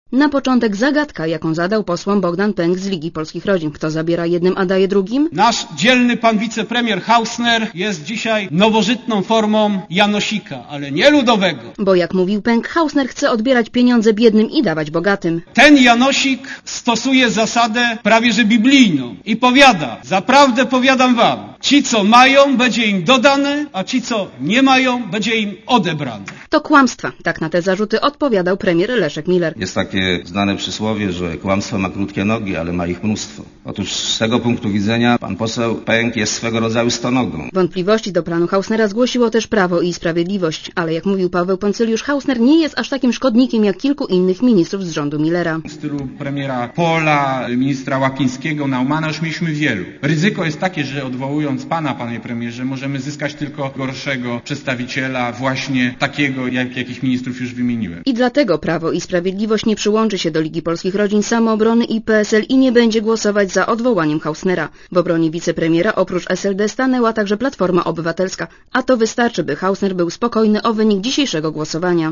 Posłuchaj relacji reporterki Radia Zet (280 KB)